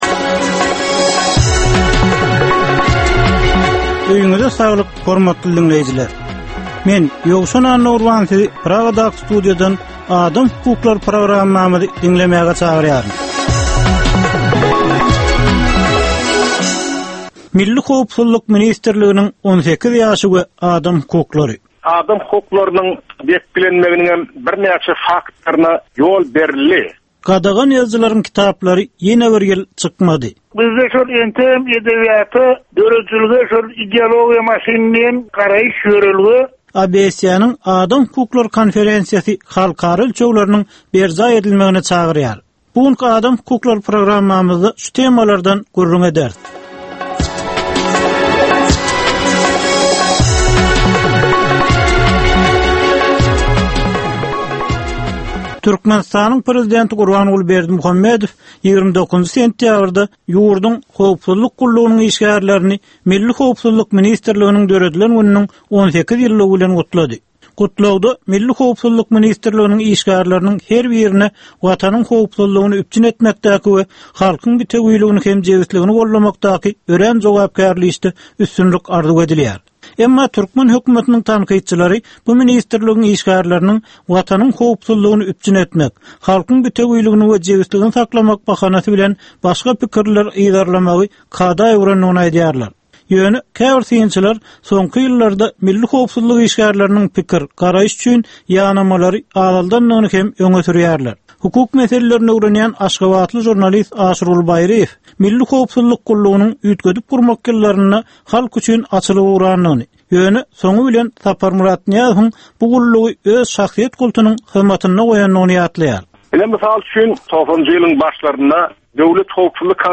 Türkmenistandaky ynsan hukuklarynyň meseleleri barada 15 minutlyk ýörite programma. Bu programmada ynsan hukuklary bilen baglanyşykly anyk meselelere, problemalara, hadysalara we wakalara syn berilýar, söhbetdeşlikler we diskussiýalar gurnalýar.